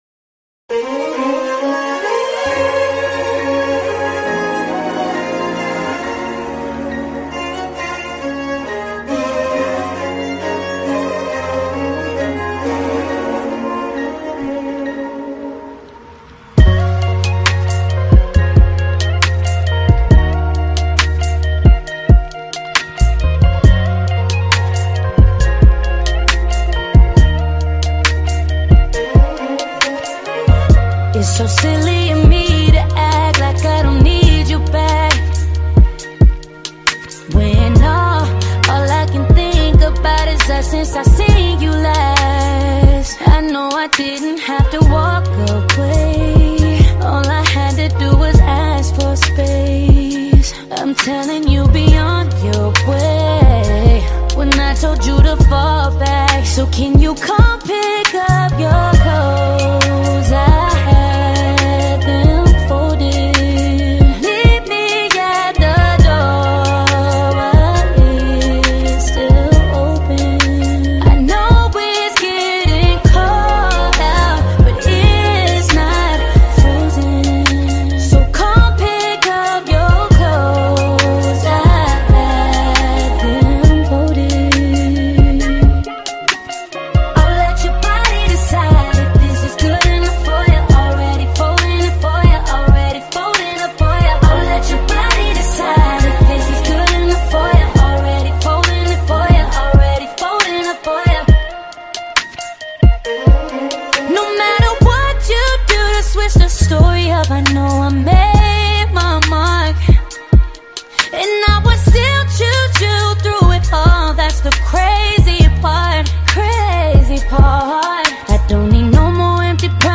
Rhythm and Soul